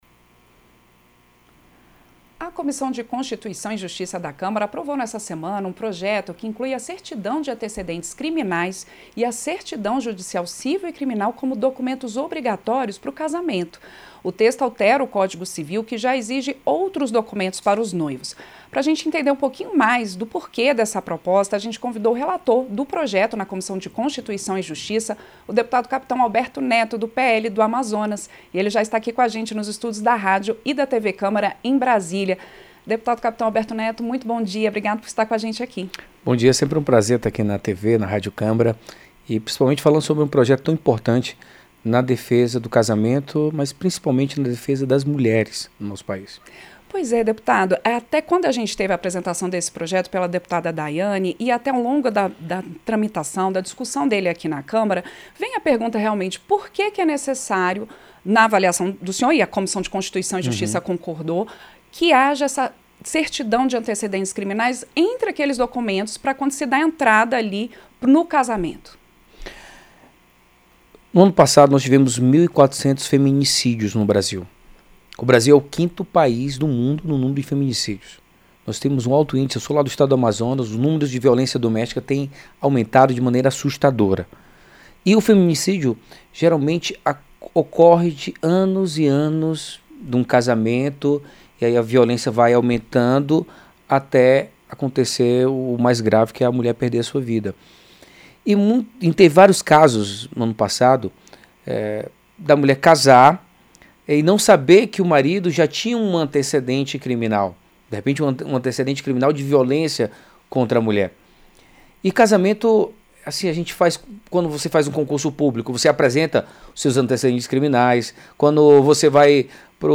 Entrevista – Dep. Capitão Alberto Neto (PL-AM)